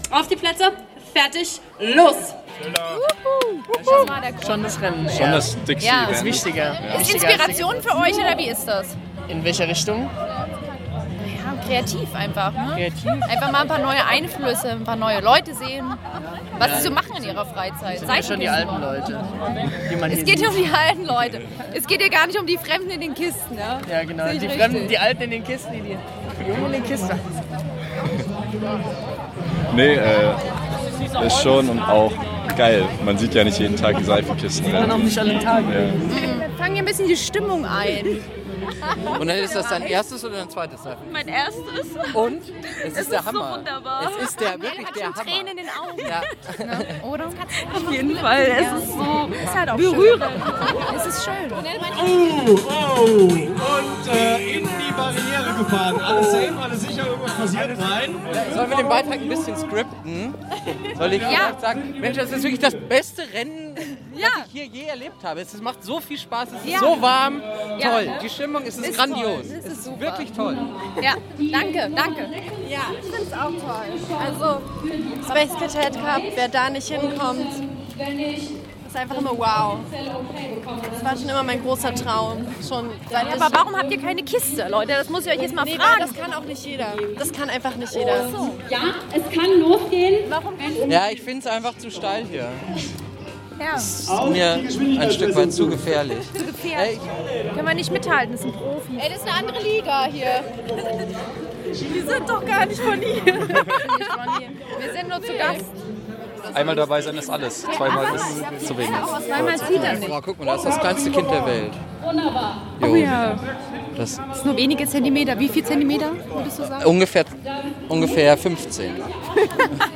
Anstatt uns selbst als Reporter auf die Strecke zu schicken, haben wir dieses Jahr das Mikrofon einfach an eine Bauingenieurin weitergegeben.
Mitwirkende: Ein paar angehende Bauingenieure, Baumanager, Medienwissenschaftler, die Kommentatoren und eine verkaufte Seifenkiste